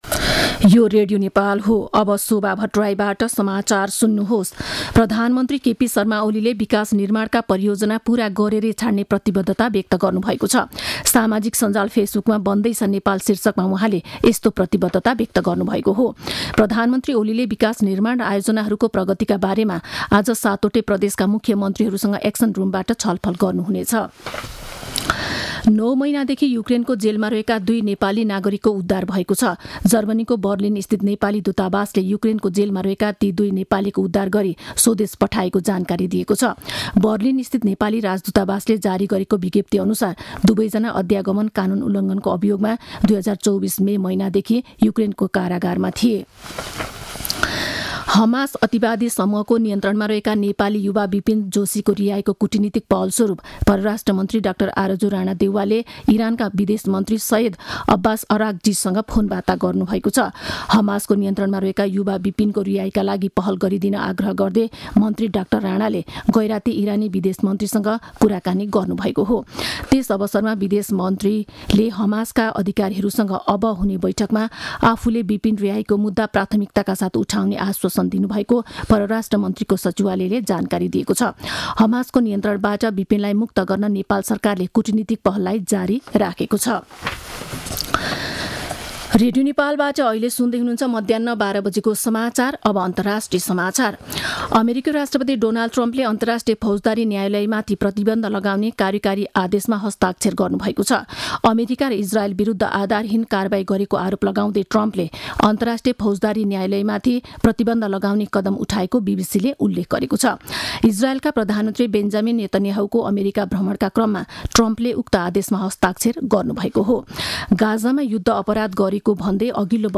मध्यान्ह १२ बजेको नेपाली समाचार : २६ माघ , २०८१